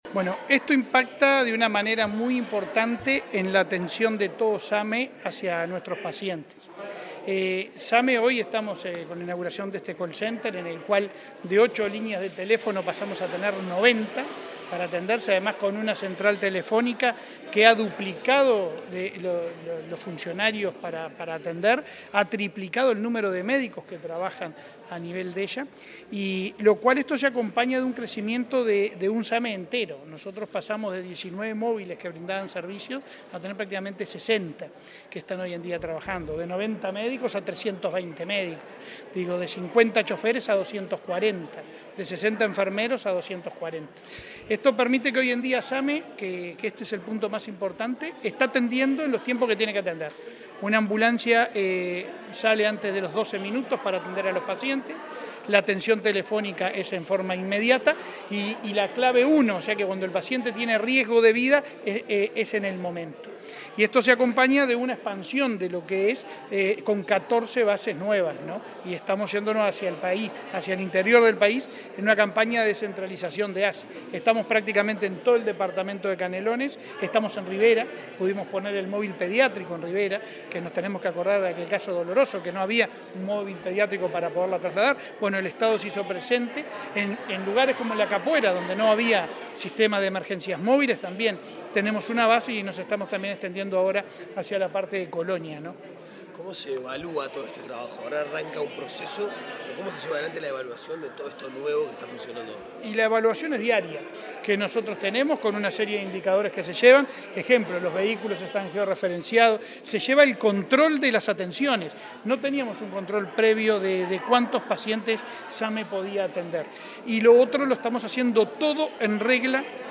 Declaraciones del presidente de ASSE, Leonardo Cipriani
Declaraciones del presidente de ASSE, Leonardo Cipriani 28/02/2023 Compartir Facebook X Copiar enlace WhatsApp LinkedIn La Administración de los Servicios de Salud del Estado (ASSE) inauguró el lunes 27 un centro de atención telefónica y logístico del Sistema de Atención Médica de Emergencia (SAME 105). El presidente del organismo, Leonardo Cipriani, participó en el acto y luego dialogó con la prensa.